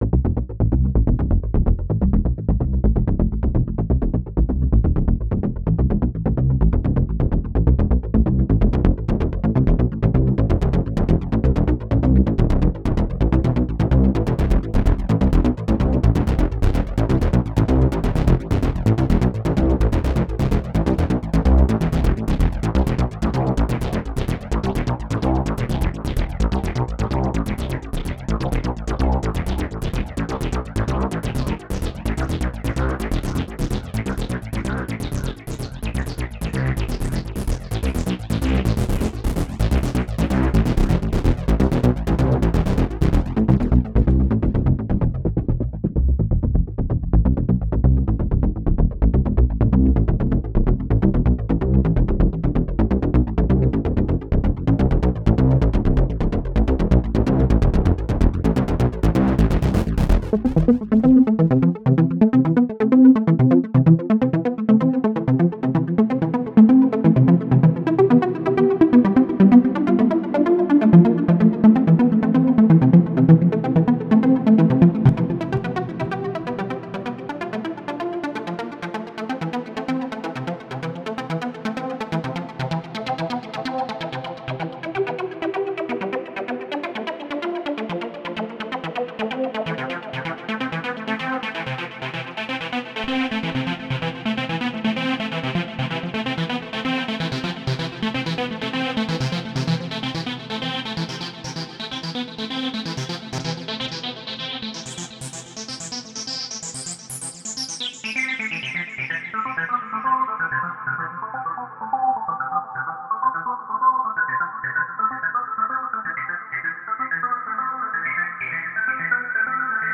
roland_sh-201_-_sounds_demo_-_impressions.mp3